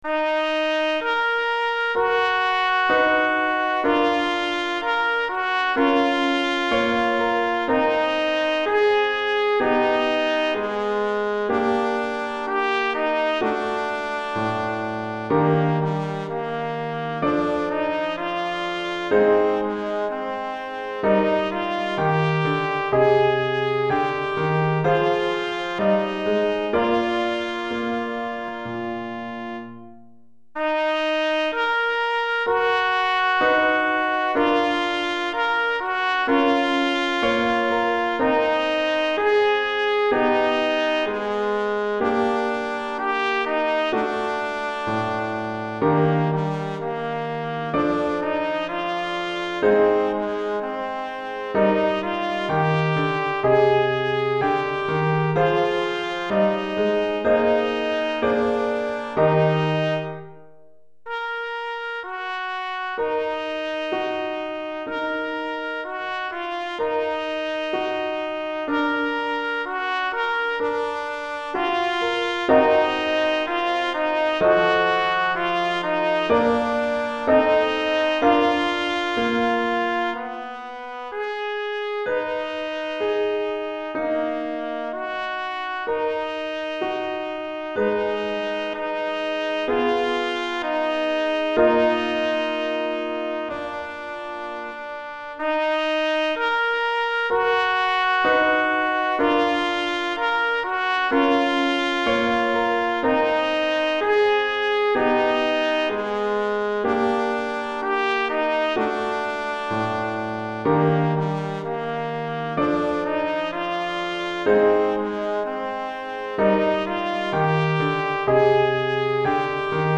Trompette en Sib et Piano